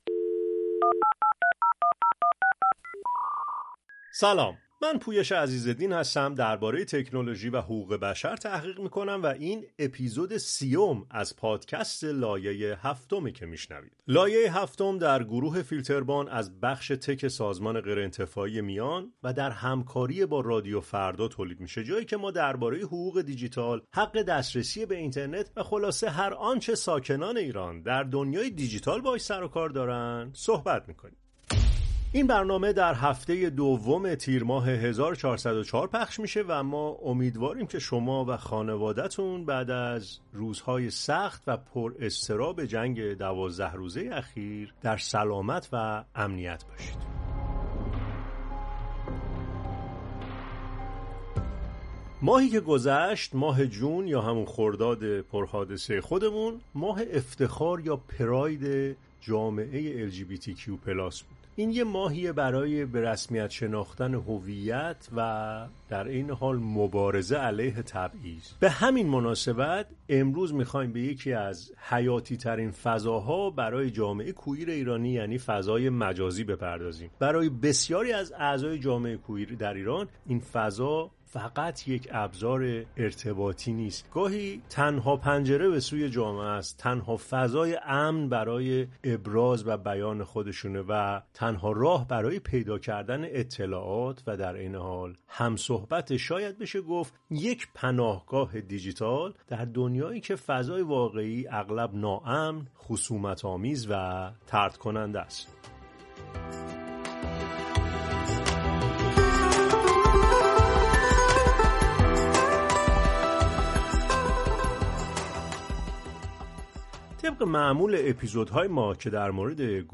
این گفتگو به موضوعات کلیدی مانند چالش‌های بیان هویت، خطرات «شبکه ملی اطلاعات» برای حریم خصوصی، تبعیض الگوریتمی و جنگ با دروغ‌پراکنی می‌پردازد و داستان‌های الهام‌بخش مقاومت و خلاقیت برای بقا را روایت می‌کند.